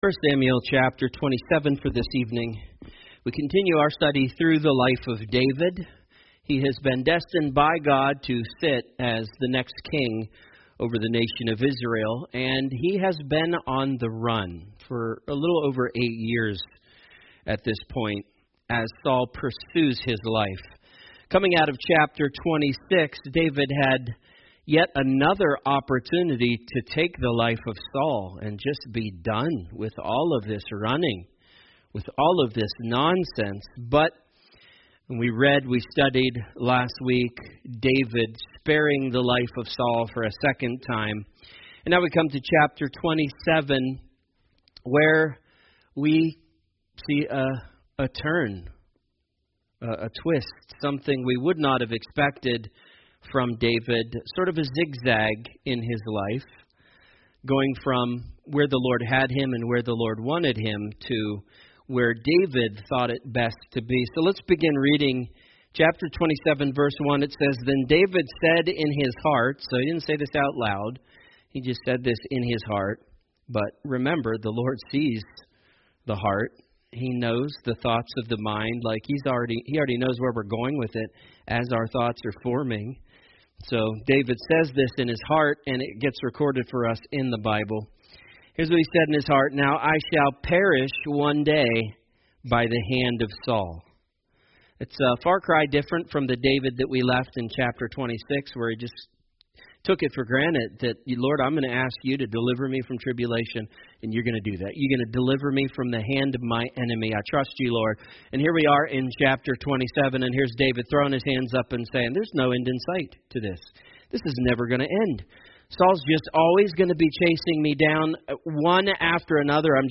Message